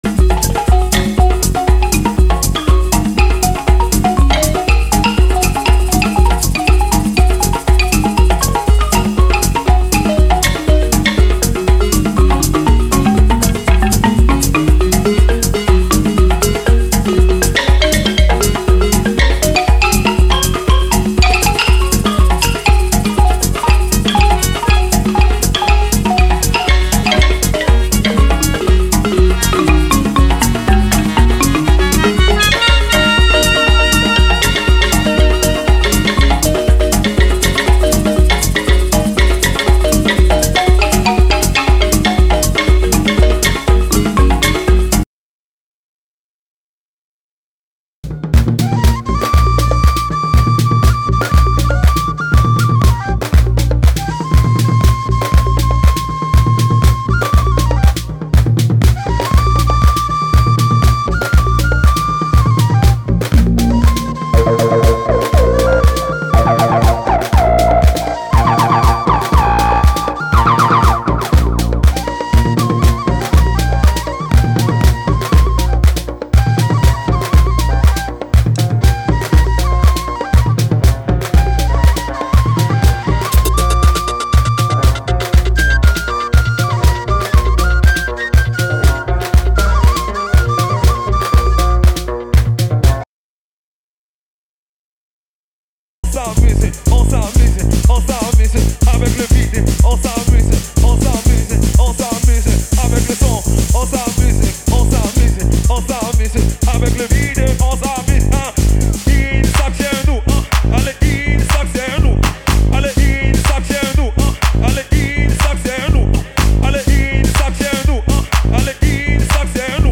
エスニックな音色をダンサンブルなトラックに取り入れた3トラック。